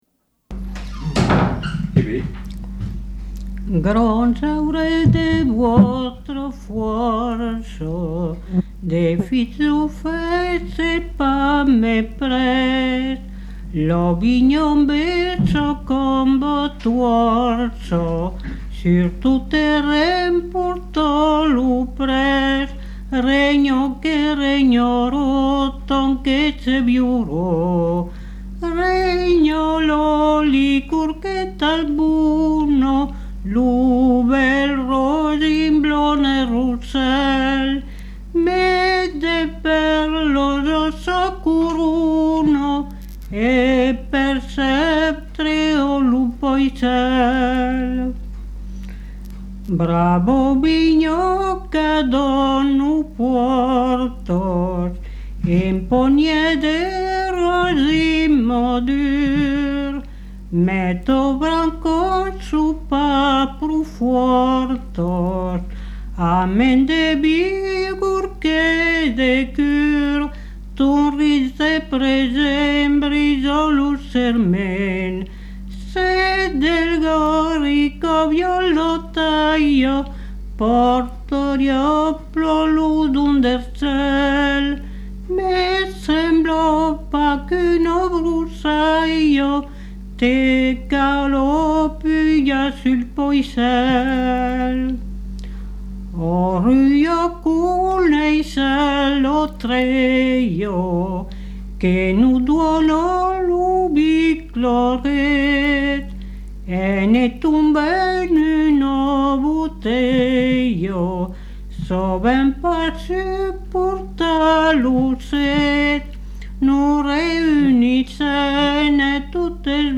Aire culturelle : Rouergue
Lieu : Rignac
Genre : chant
Effectif : 1
Type de voix : voix de femme
Production du son : chanté